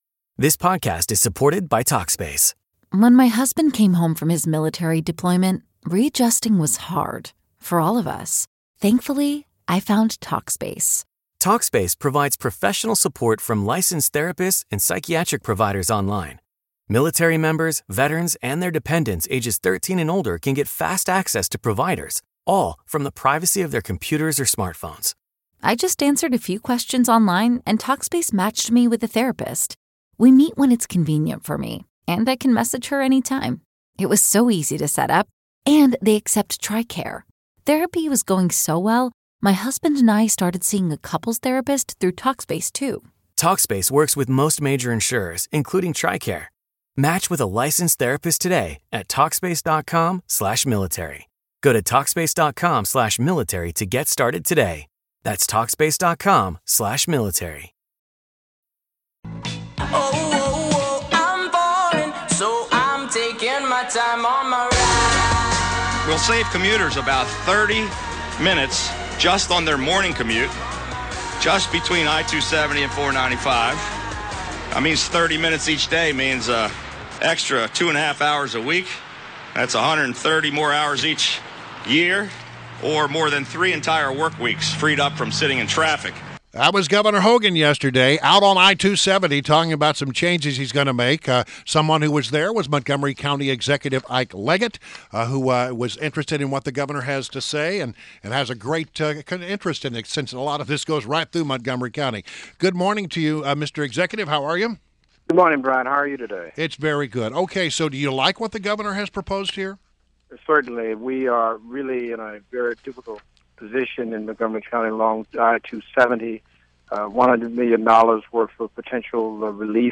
INTERVIEW – Montgomery County Executive ISIAH “IKE” LEGGETT